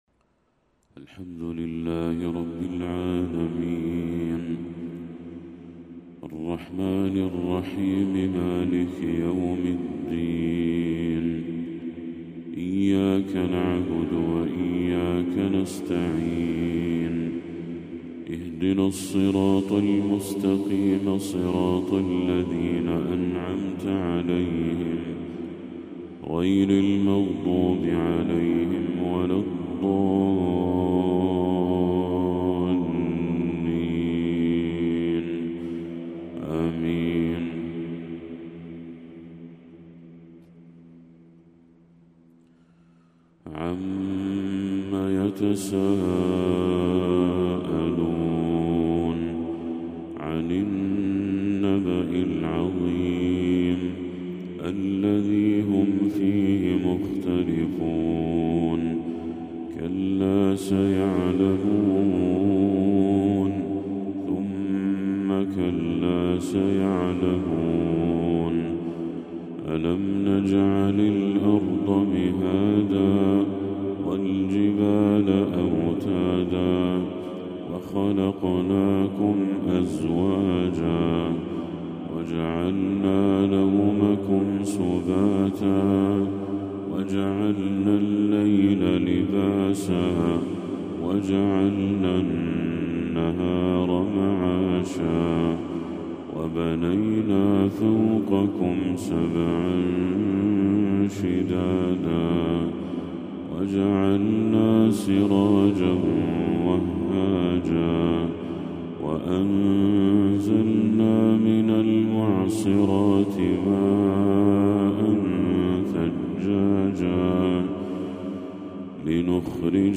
تلاوة خاشعة لسورة النبأ كاملة للشيخ بدر التركي | فجر 14 ربيع الأول 1446هـ > 1446هـ > تلاوات الشيخ بدر التركي > المزيد - تلاوات الحرمين